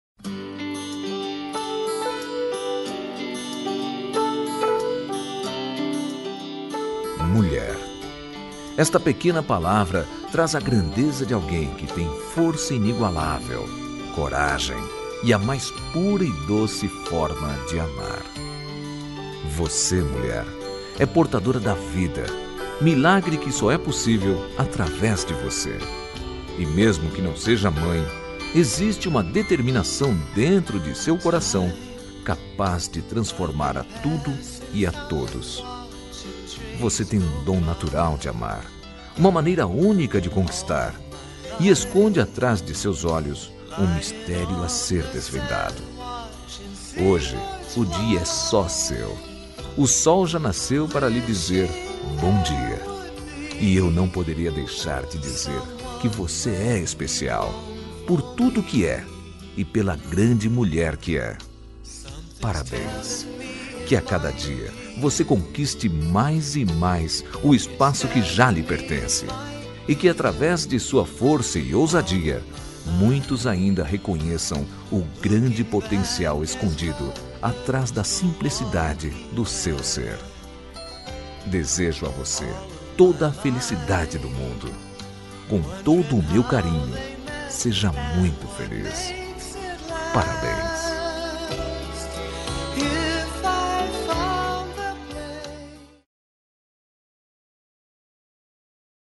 Dia Da Mulher Voz Masculina